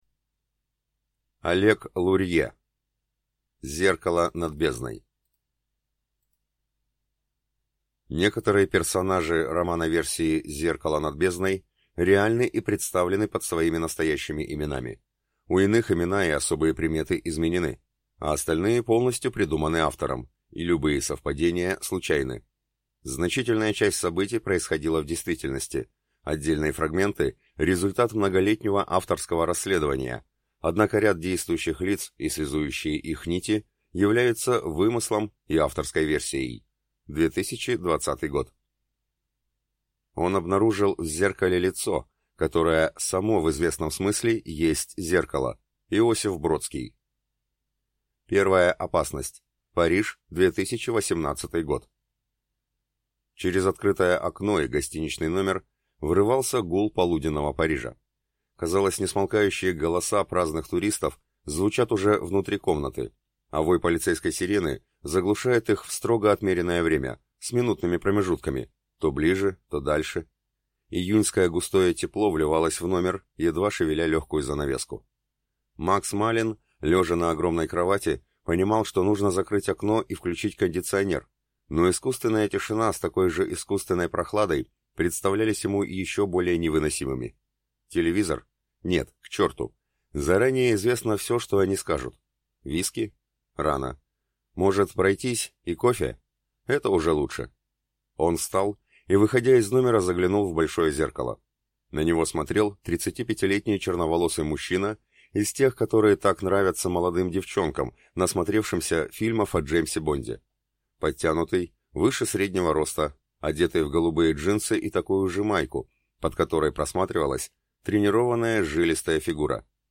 Аудиокнига Зеркало над бездной | Библиотека аудиокниг
Прослушать и бесплатно скачать фрагмент аудиокниги